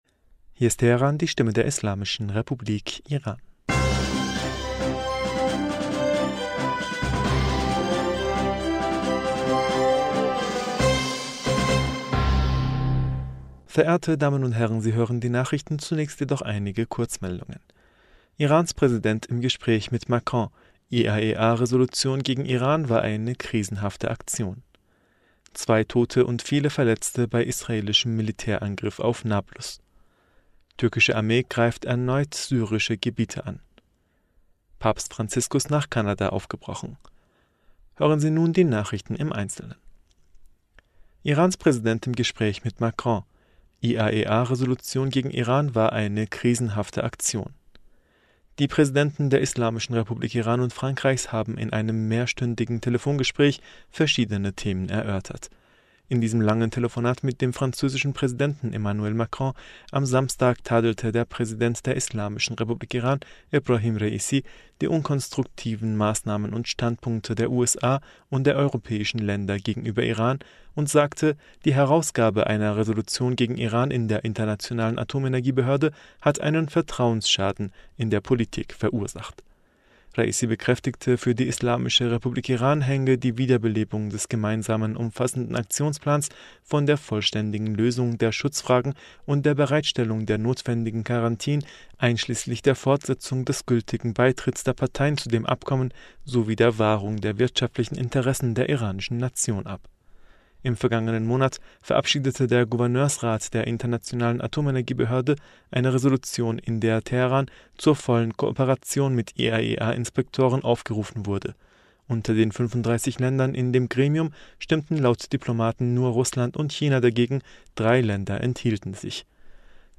Nachrichten vom 24. Juli 2022